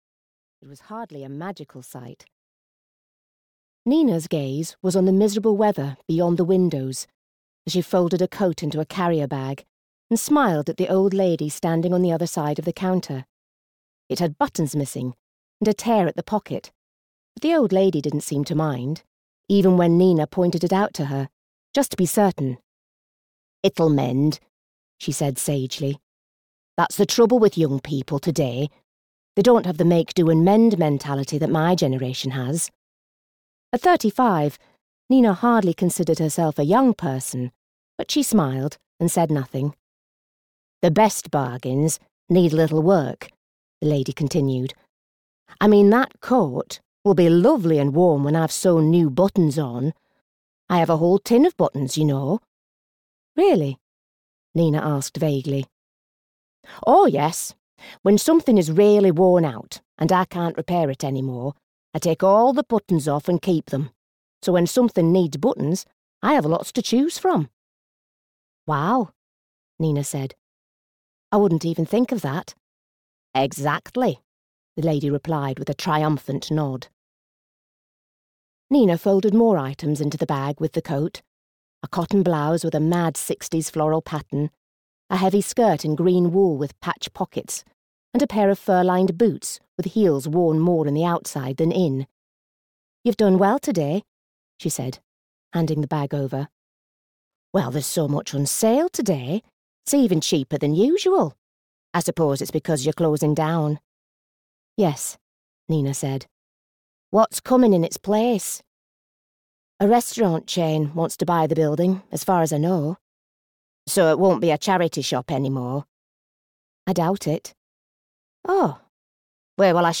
The Garden on Sparrow Street (EN) audiokniha
Ukázka z knihy